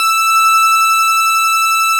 Added more instrument wavs
snes_synth_076.wav